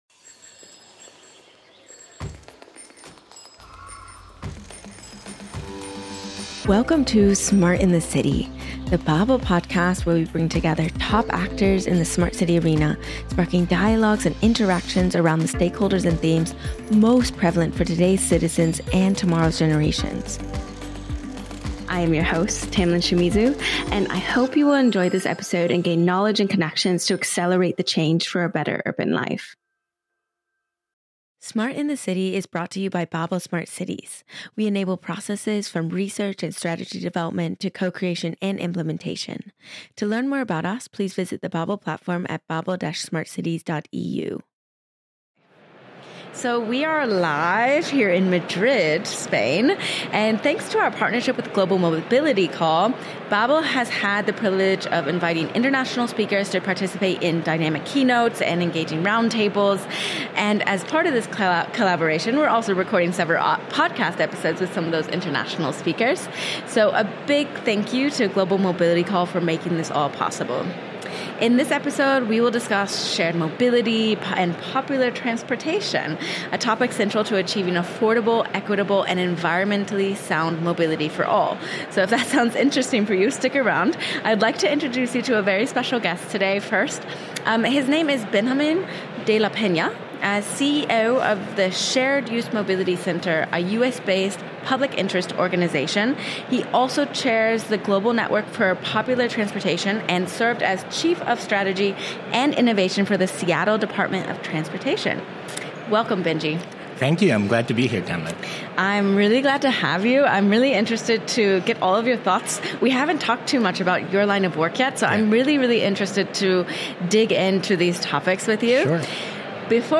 In this episode recorded live at the Global Mobility Call 2024 in Madrid